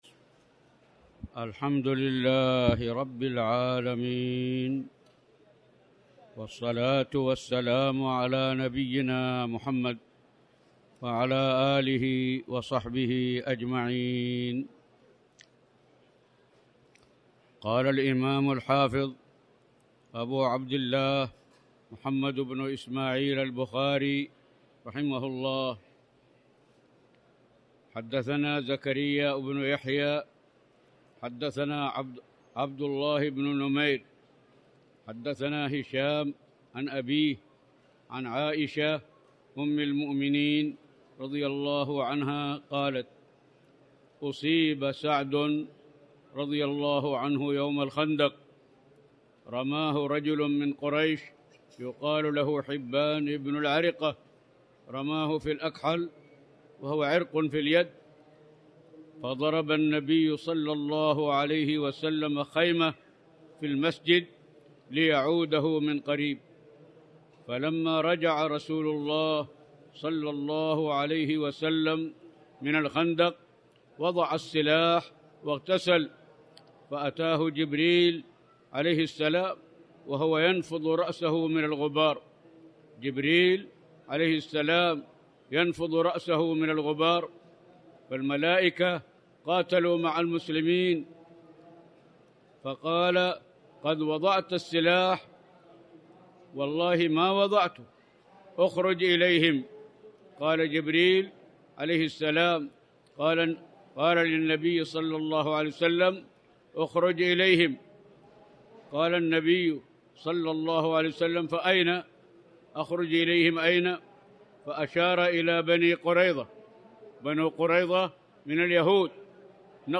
تاريخ النشر ١٦ شعبان ١٤٣٩ هـ المكان: المسجد الحرام الشيخ